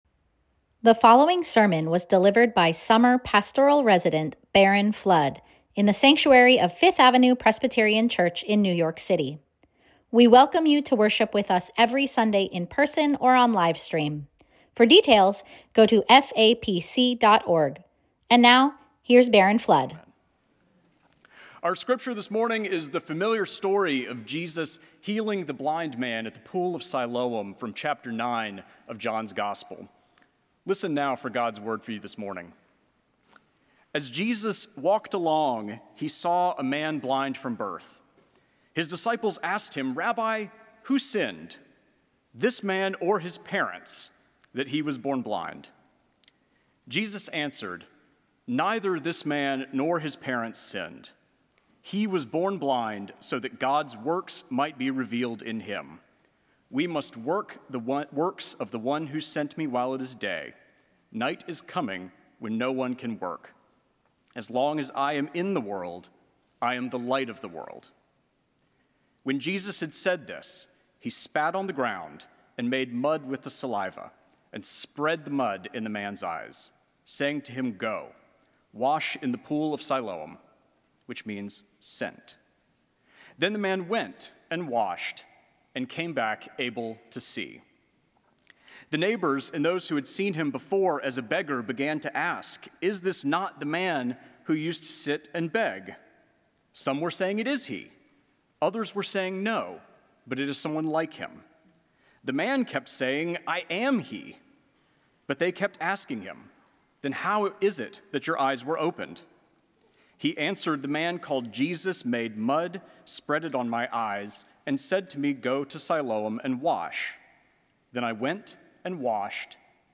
Sermons at FAPC